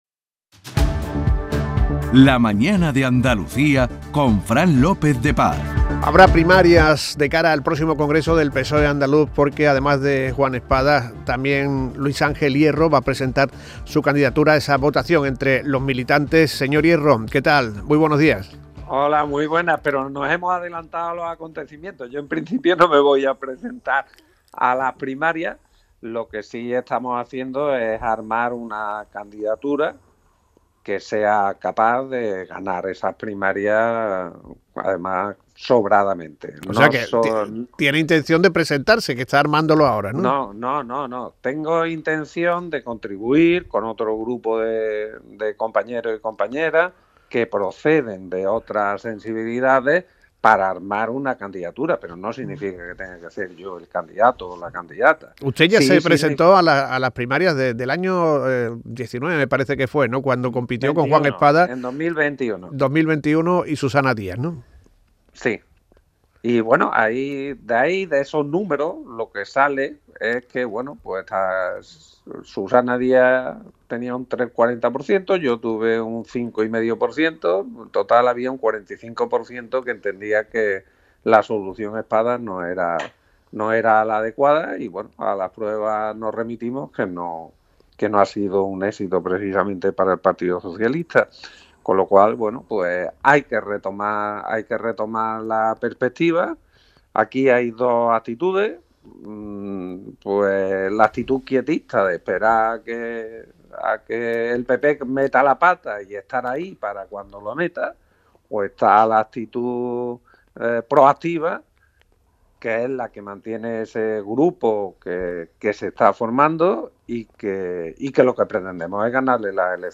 Programa informativo y de entretenimiento